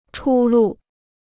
出路 (chū lù): a way out (lit. and fig.); opportunity for advancement.